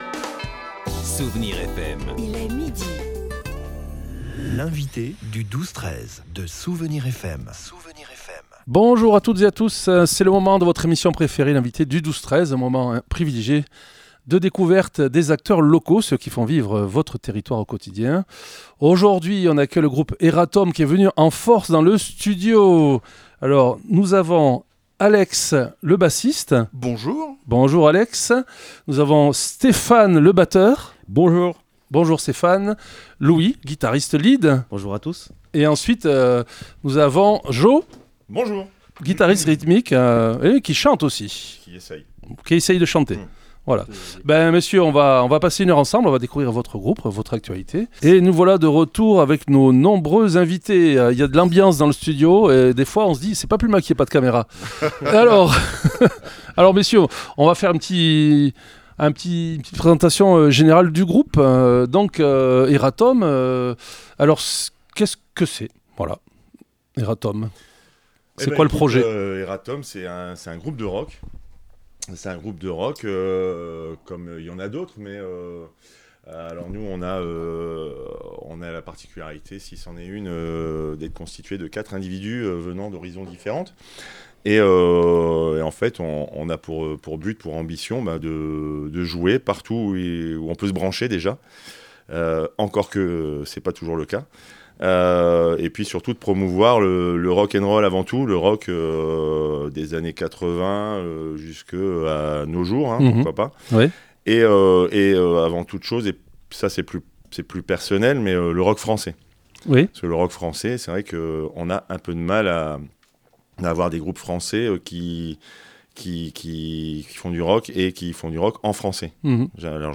Un groupe de joyeux drilles résoluments rock'n roll !